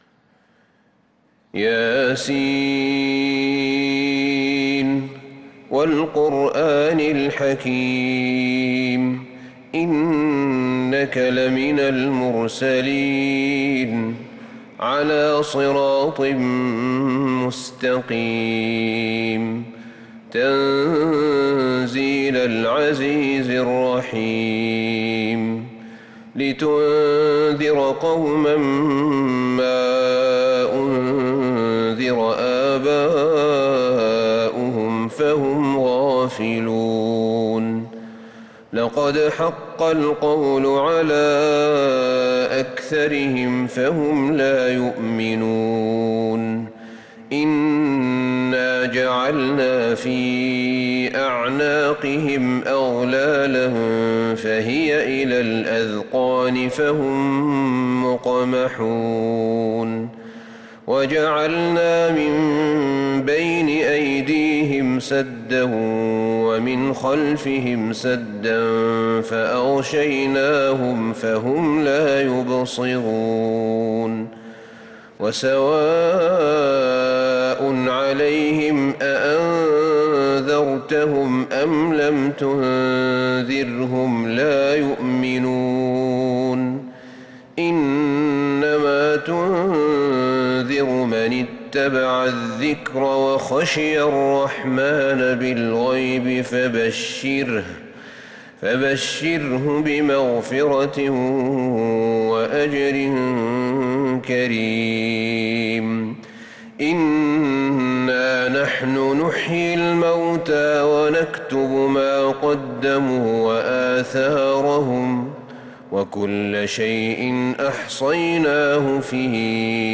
سورة يس كاملة للشيخ أحمد بن طالب | فجريات شهر محرم 1446هـ > السور المكتملة للشيخ أحمد بن طالب من الحرم النبوي 2 🕌 > السور المكتملة 🕌 > المزيد - تلاوات الحرمين